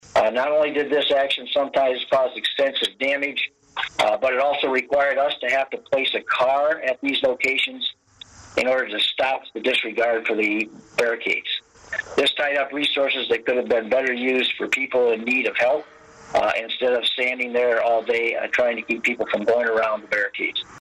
COLDWATER, MI (WTVB) – A frustrated Branch County Sheriff John Pollack called out drivers during Tuesday’s Branch County Board of Commissioners work meeting who disregarded the “Road Closed” barricades that were put up following last Thursday’s devastating storm.